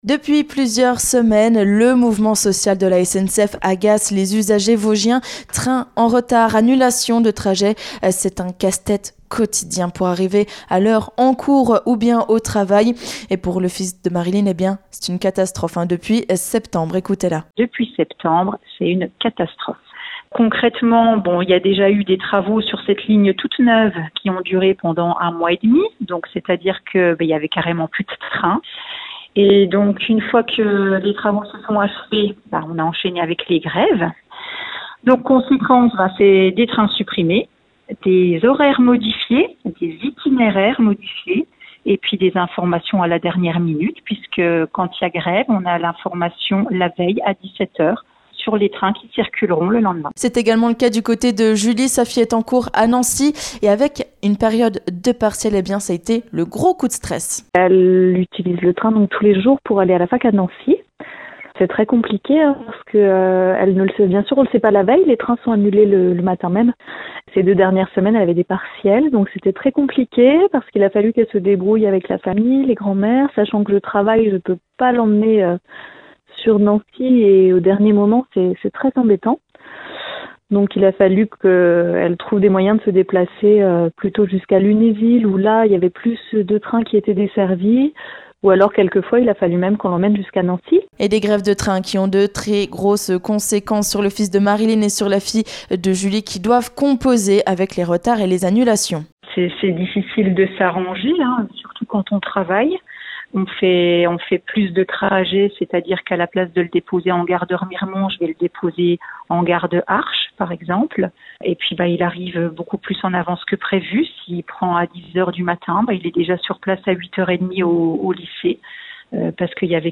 Retrouvez le témoignage de deux usagers SNCF qui vivent quotidiennement les conséquences du mouvement social la SNCF depuis plusieurs semaines, voire mois, dans le Grand Est.